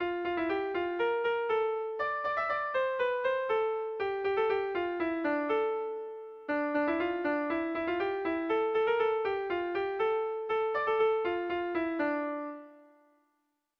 Gabonetakoa
ABDEF